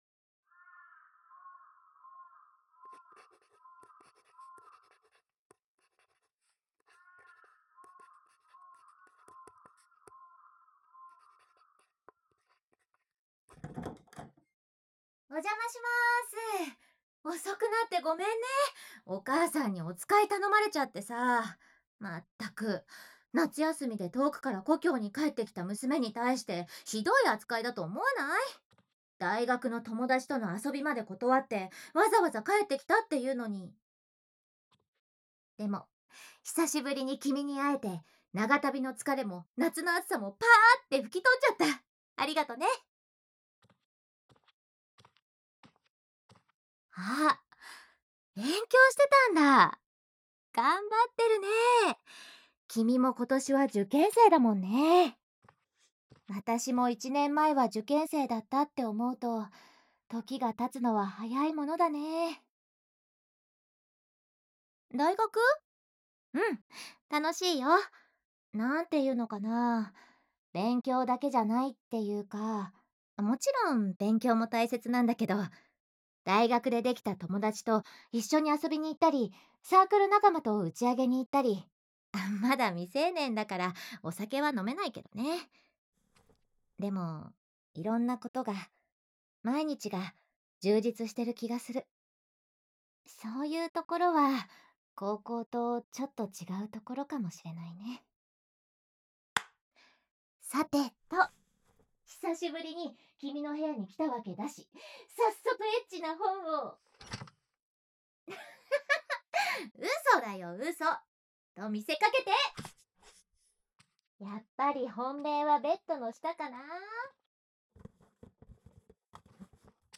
环绕音 ASMR